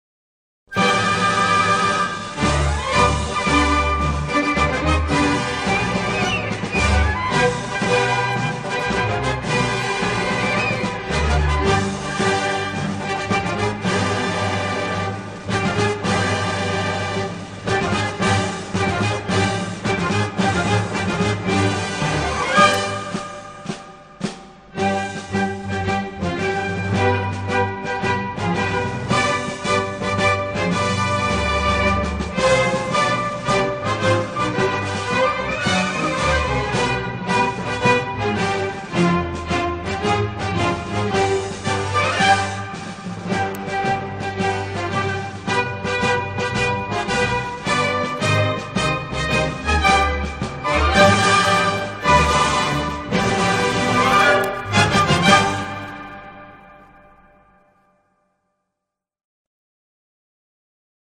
سرود کوتاه